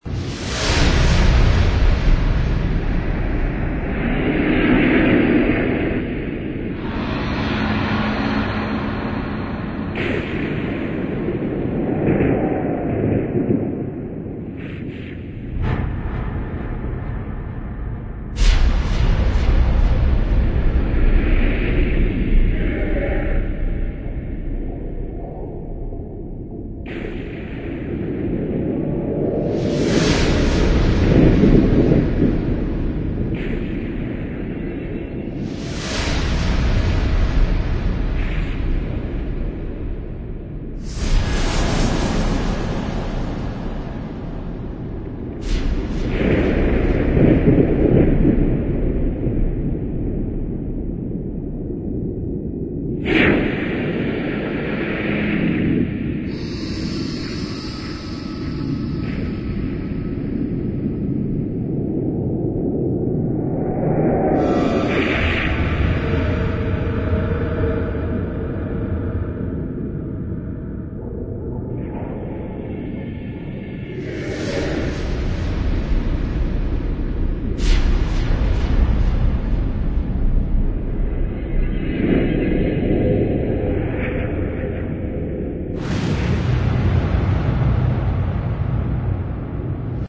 AMBIENCES